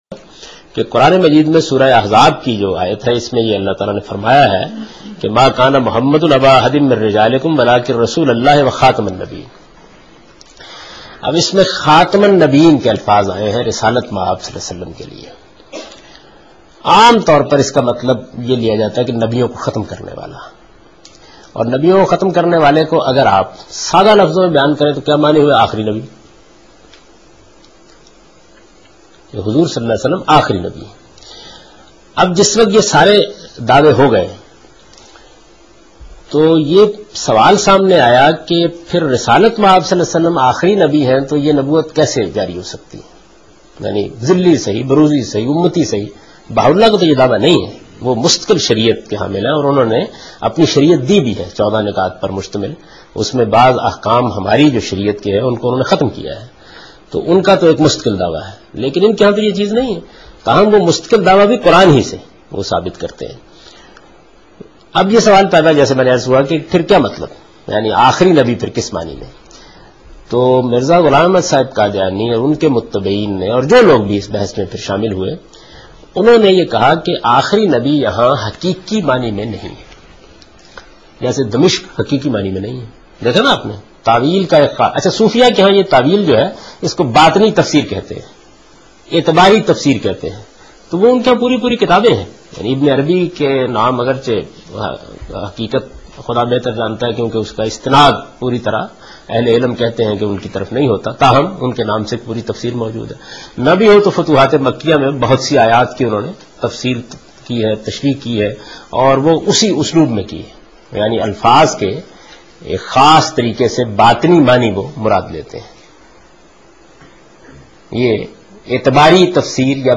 Javed Ahmed Ghamidi: From Meezan Lectures